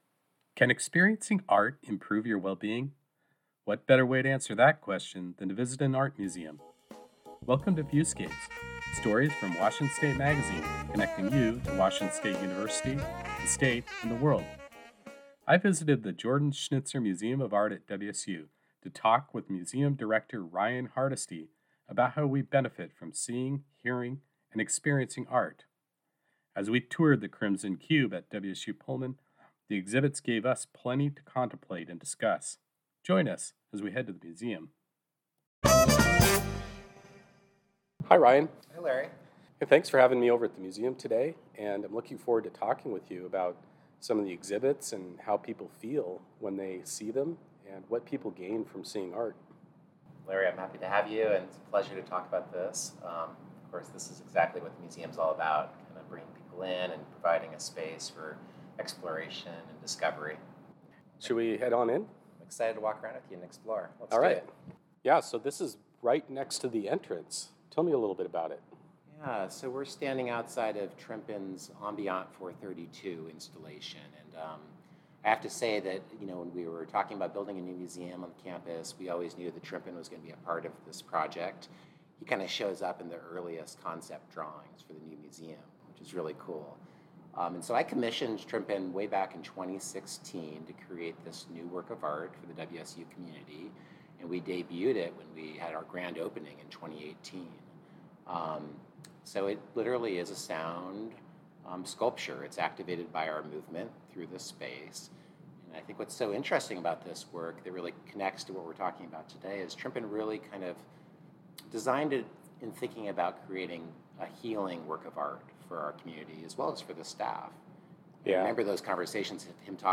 on a tour of the museum in WSU Pullman’s Crimson Cube